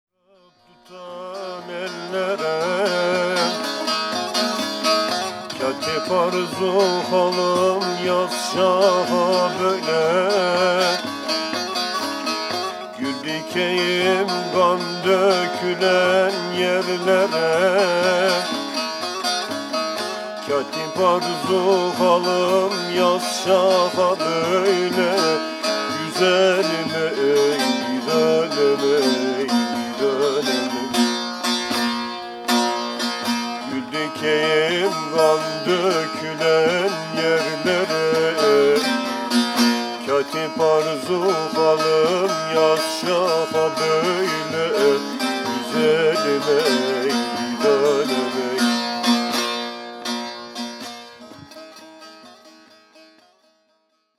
Genres: Turkish Traditional, Folk.